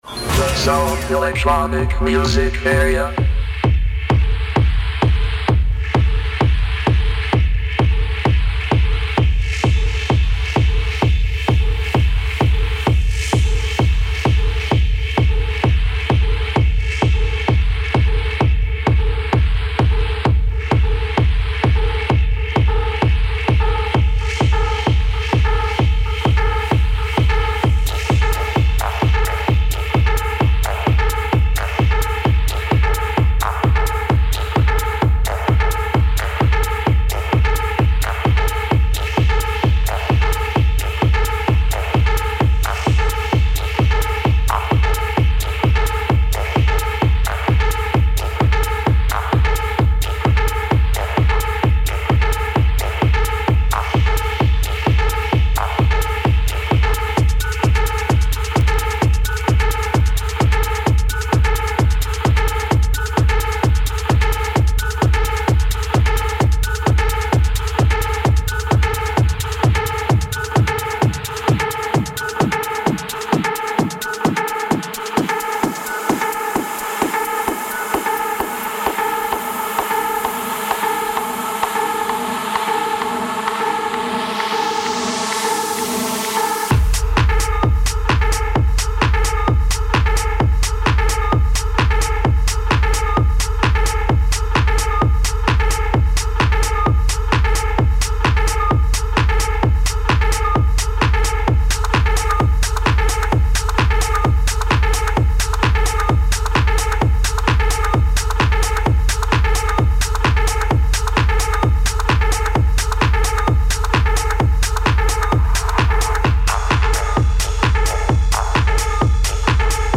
A Very special Live show